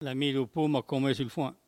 Patois
Locution